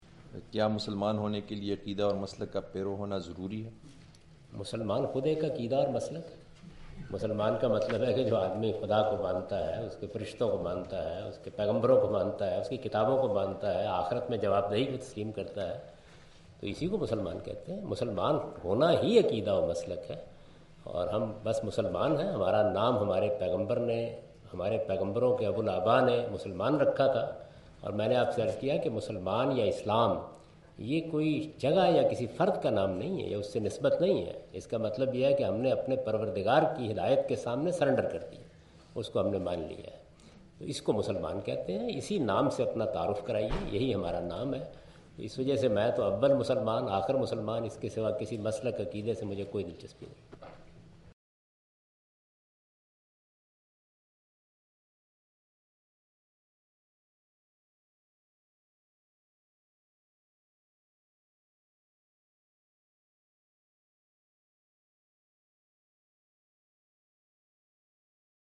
Javed Ahmad Ghamidi answer the question about "is it necessary to adopt any sect to become a Muslim?" during his visit to Queen Mary University of London UK in March 13, 2016.